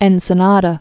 (ĕnsə-nädə)